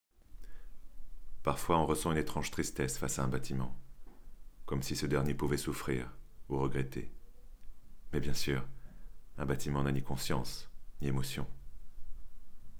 - Basse